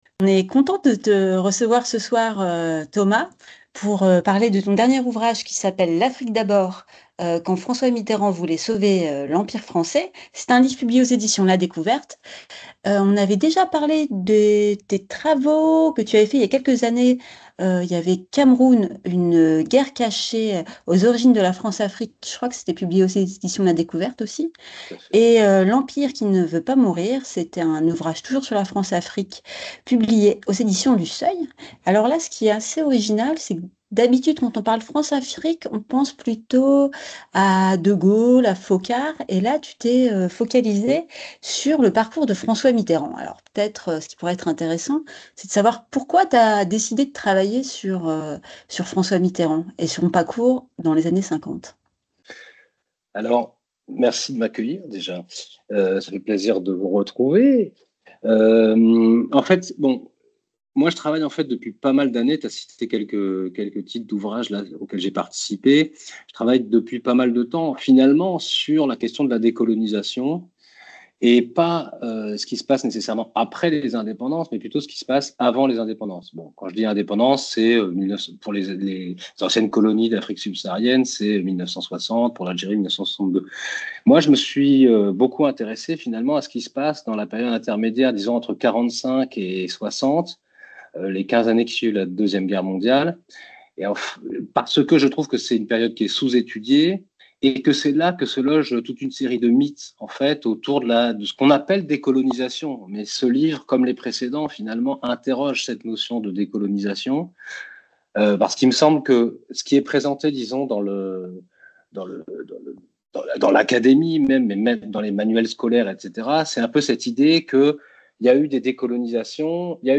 En complément, dans l’archive du jour, on vous propose une nouvelle diffusion d’un entretien avec Daniel Bensaïd autour de la Toussaint rouge et de la position de la gauche face à la décolonisation à partir des années 1950 en Algérie et en Afrique subsaharienne.